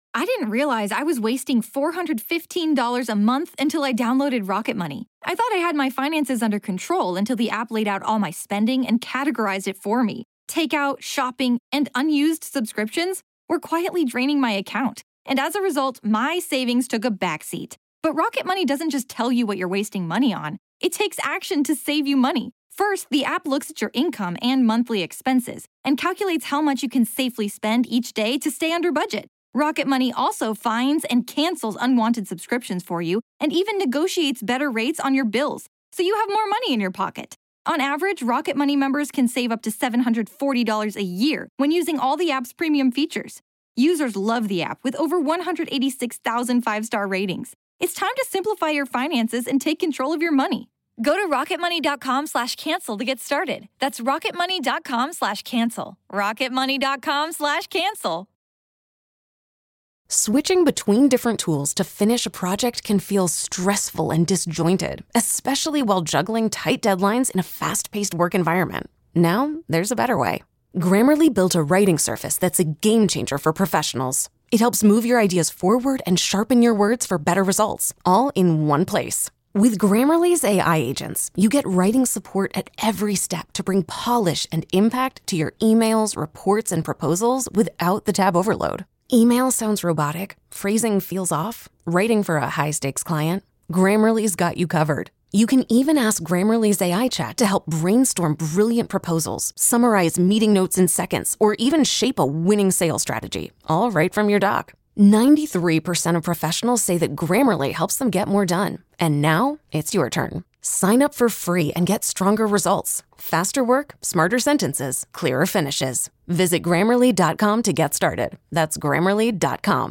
On today's show, we tried out a newer, slightly more rude, way of trying to move through random topics. By shouting "NEXT" when it's time to move on to the next topic